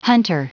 Prononciation du mot hunter en anglais (fichier audio)
Prononciation du mot : hunter